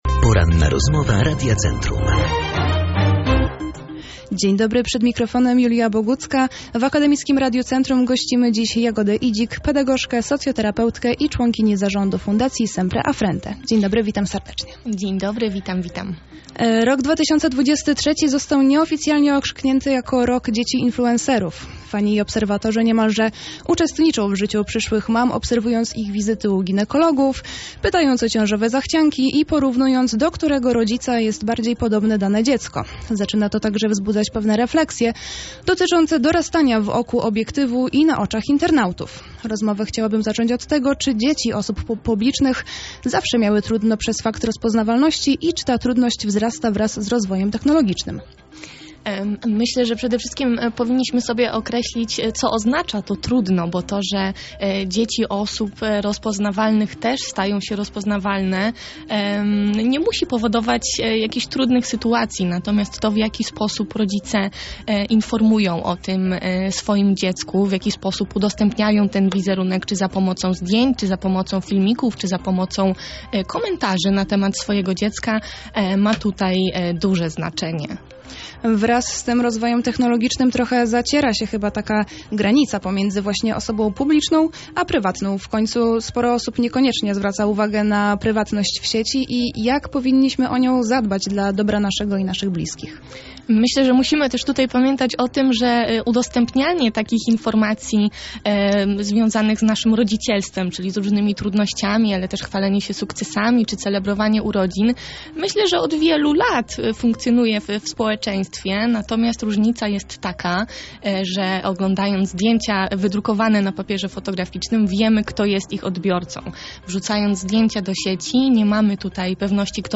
Czy i jak powinniśmy zapobiegać udostępnianiu zdjęć małoletnich w sieci? Odpowiedź na to i inne pytania poznaliśmy podczas dzisiejszej Porannej Rozmowy Radia Centrum.